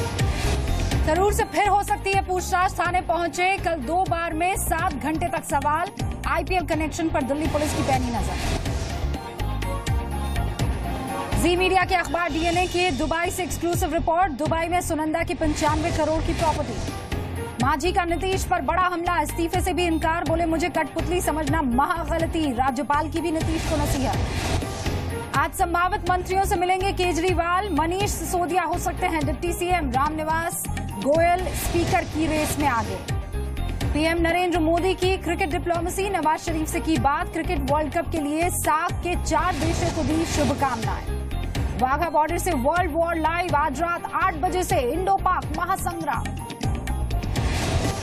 Listen to Top Headlines of the Day
Listen to Top Headlines of the Day on Zee News.